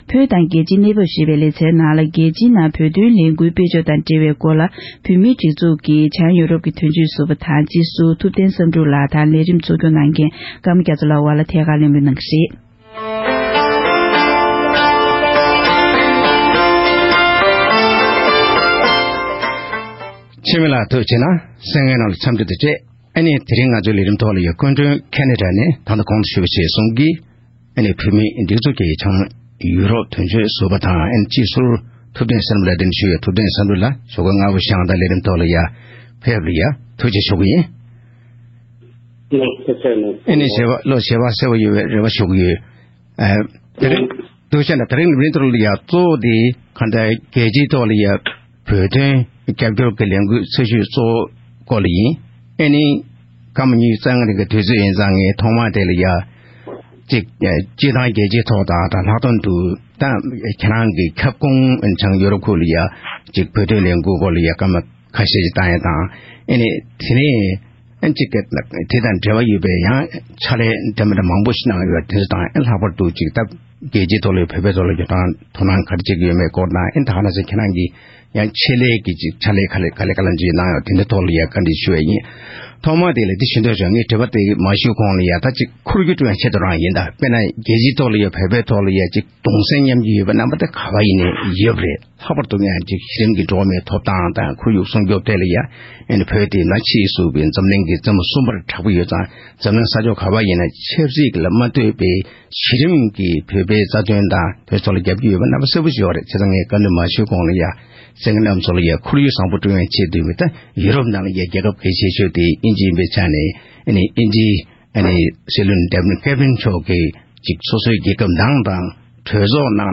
ཐད་ཀར་གླེང་མོལ་ཞུས་པར་གསན་རོགས༎